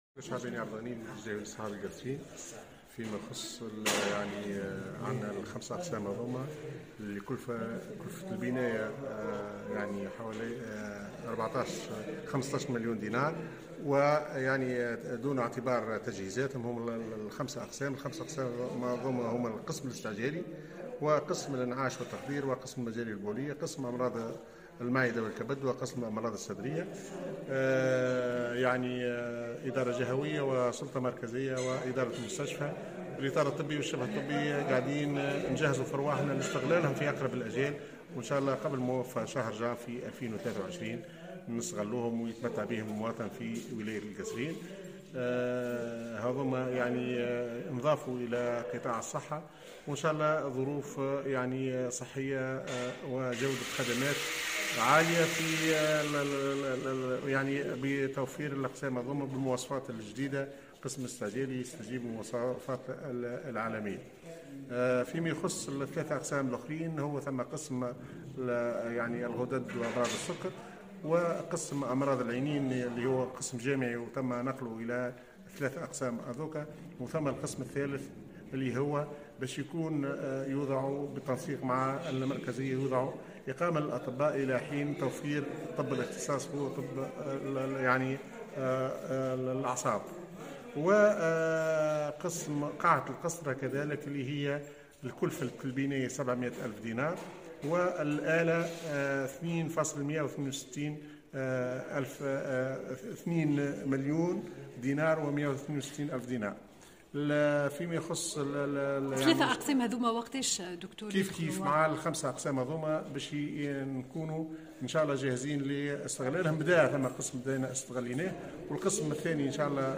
القصرين : قريبا دخول 8 أقسام جديدة حيز العمل الفعلي [تصريح]
أكد المدير الجهوي للصحة بالقصرين الدكتور عبد الغني الشعباني أن الأقسام الجديدة الثمانية ستدخل حيز الاستغلال الفعلي قبل موفى شهر جانفي الجاري على اقصى تقدير.